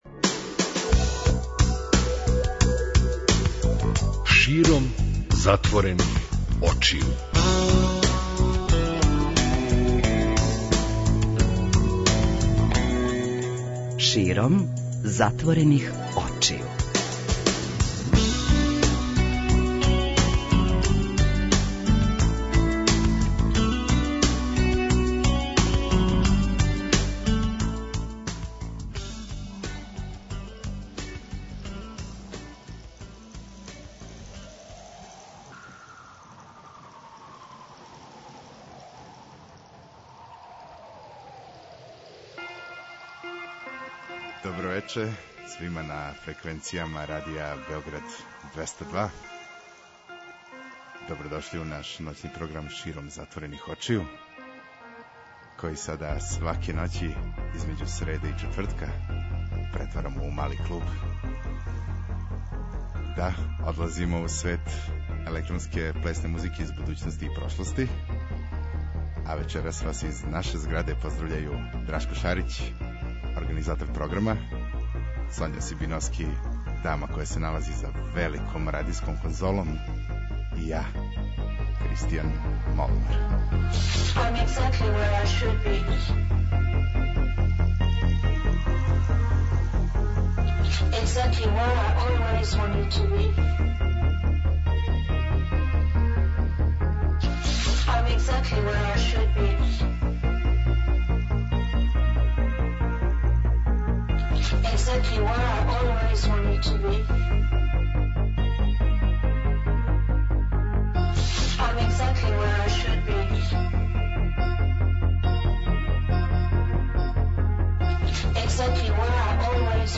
преузми : 56.64 MB Широм затворених очију Autor: Београд 202 Ноћни програм Београда 202 [ детаљније ] Све епизоде серијала Београд 202 Шта рече?!